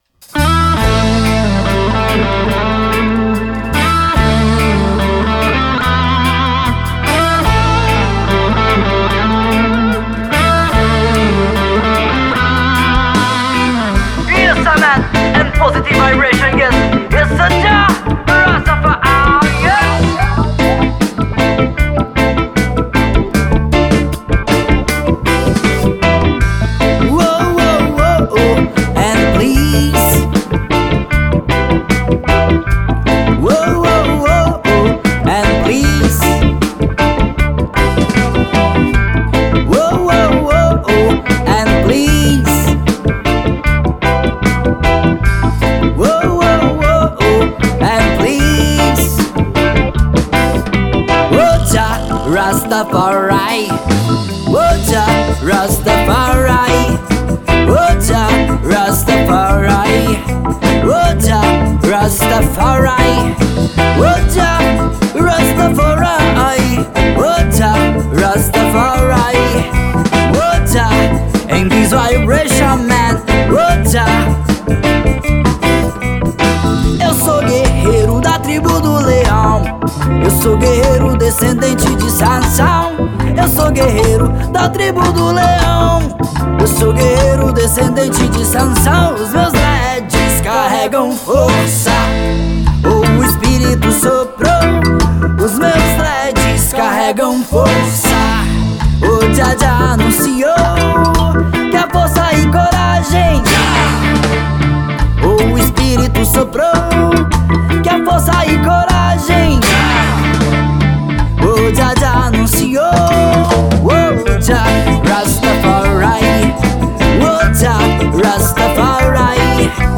1838   04:15:00   Faixa:     Reggae